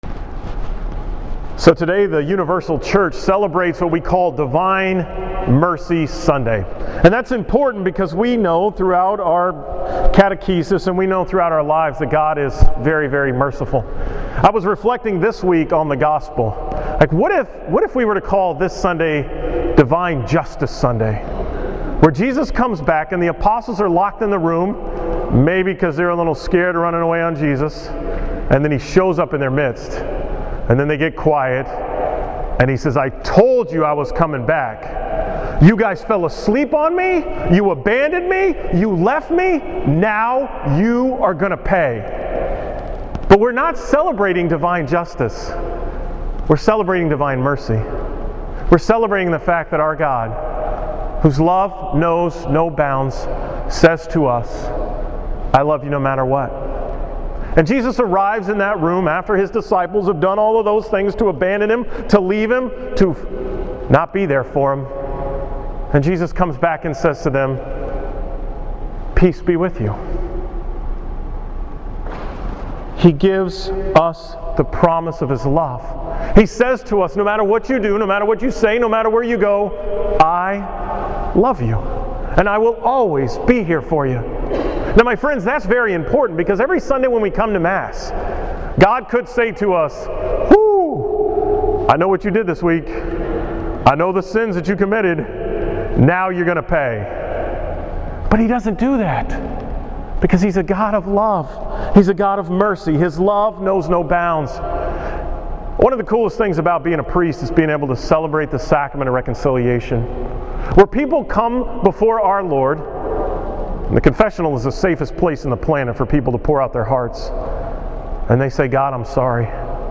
From the 8 am Mass on April 12th at St. Joseph’s in Houston